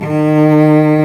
Index of /90_sSampleCDs/Roland LCDP13 String Sections/STR_Combos 1/CMB_Lrg Ensemble
STR SOLO C0O.wav